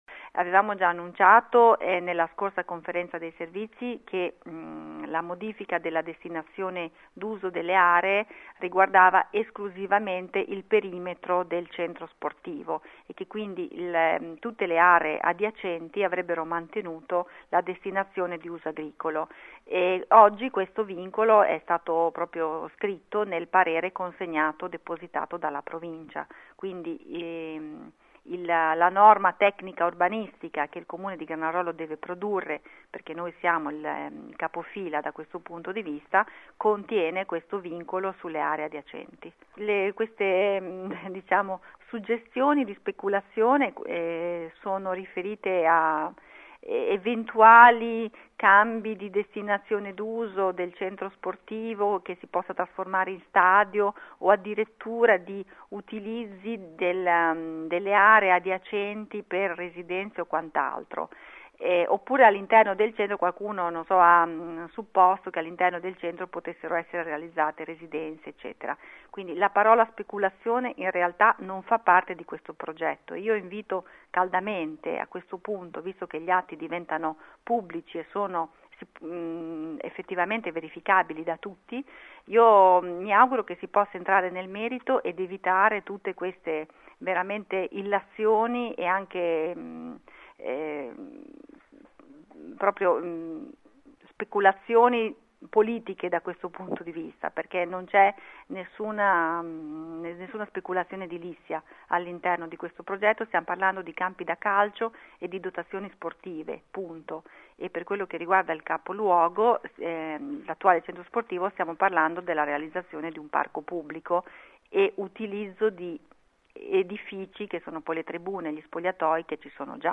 Ascolta il sindaco di Granarolo Loretta Lambertini